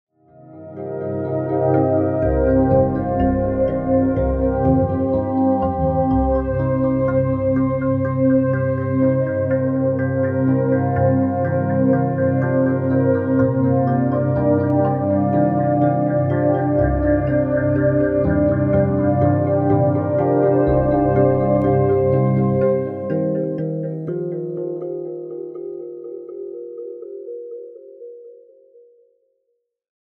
C minor